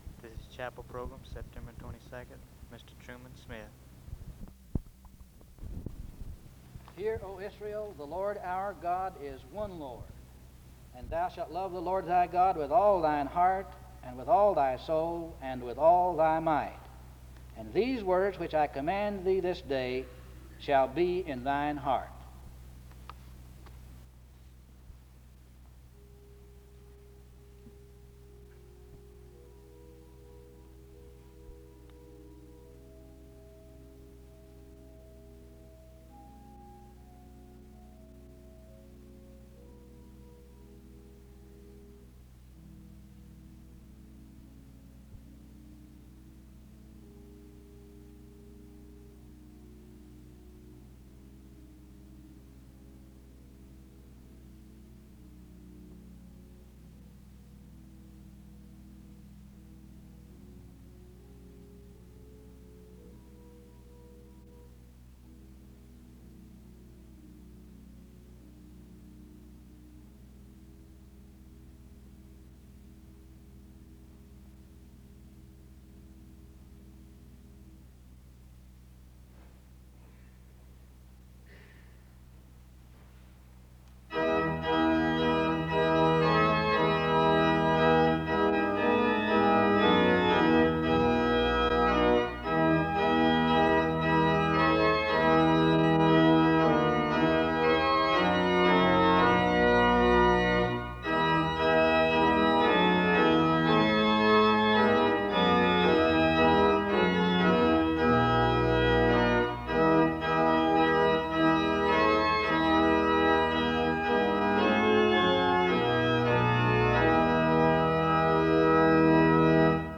The service begins with scripture reading and music from 0:00-4:54.
There is an introduction to the speaker from 5:02-6:50. There is prayer from 6:55-8:27.
SEBTS Chapel and Special Event Recordings SEBTS Chapel and Special Event Recordings